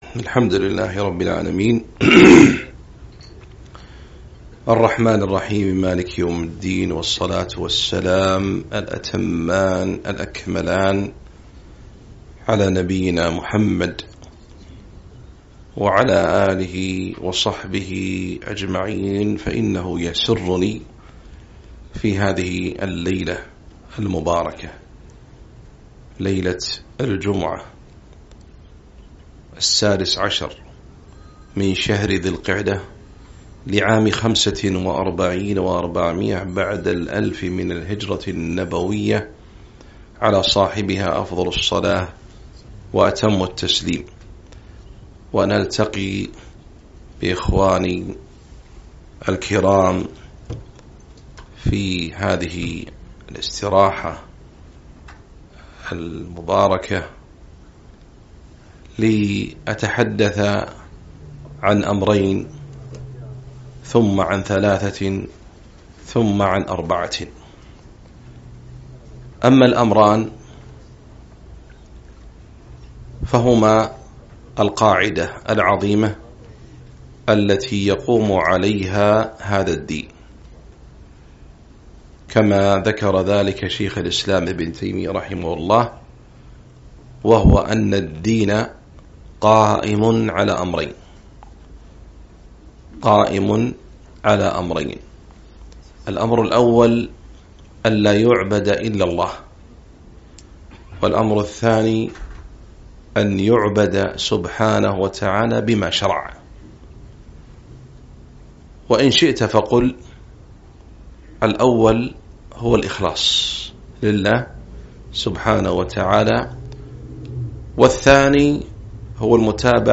محاضرة - اثنان وثلاثة وأربعة